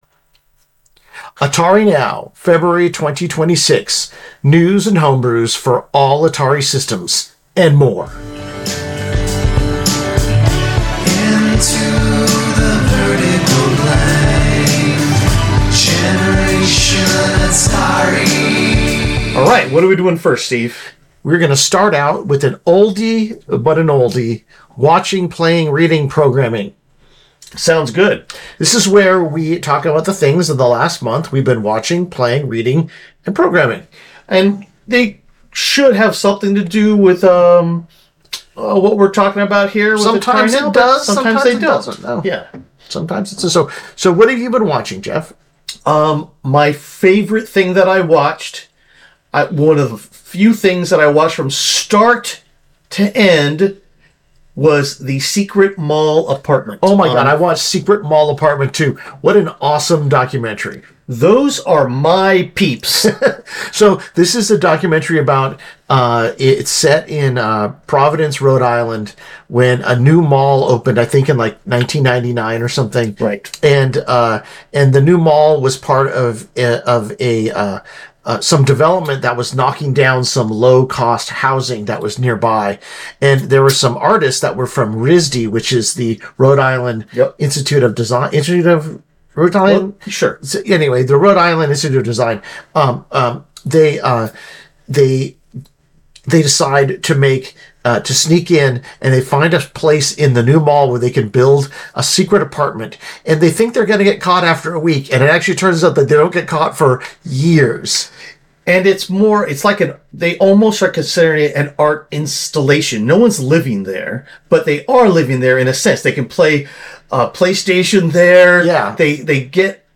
A lethargic, uninspired episode where you can tell we are over this.